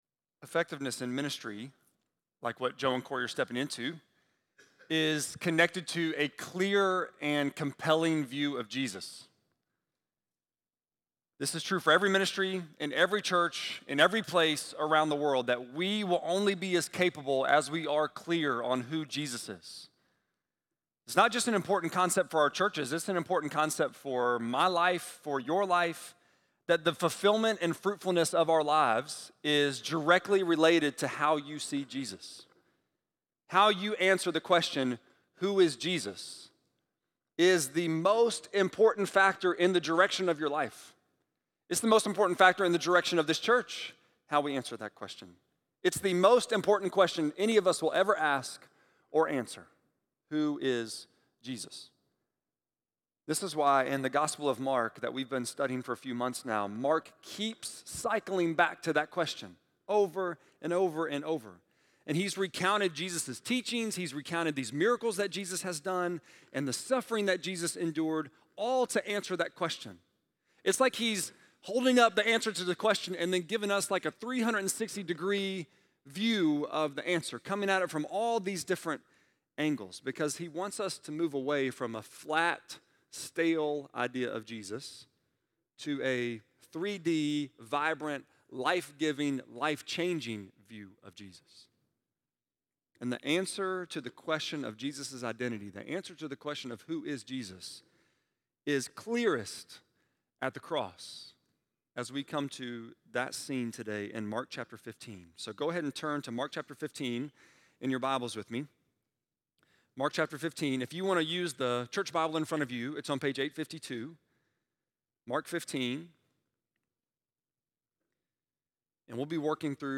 6.26-sermon.mp3